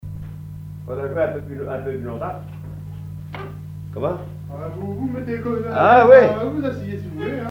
danse : scottish
répertoire d'air pour la danse au violon et à l'accordéon
Pièce musicale inédite